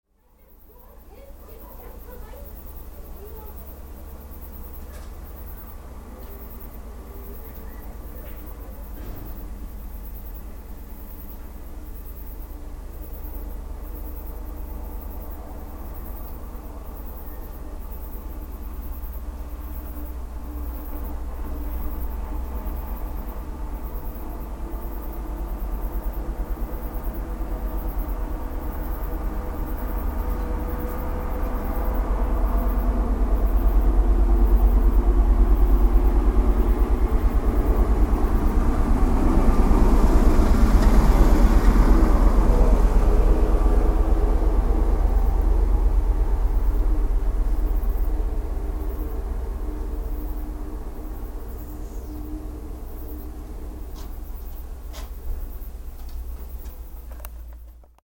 On the streets of Kazarman